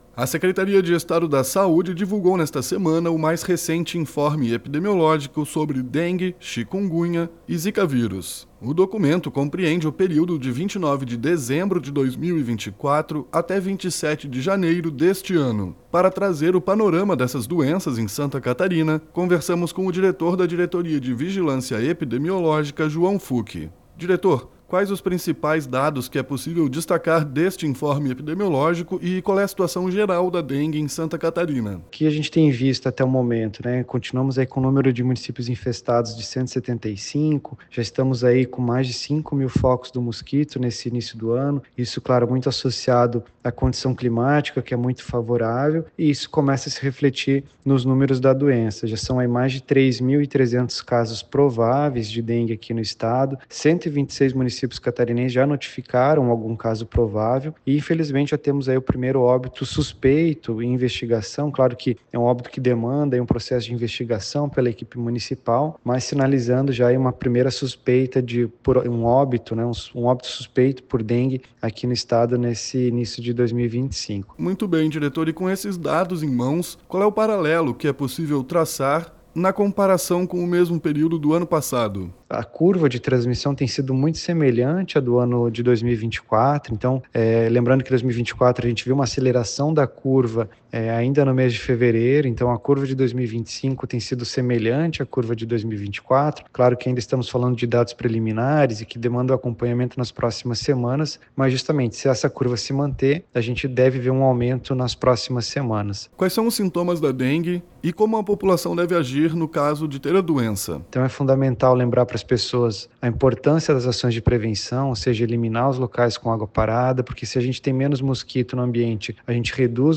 ENTREVISTA
SECOM-Entrevista-Diretor-Dive-Informe-Epidemiologico-Dengue.mp3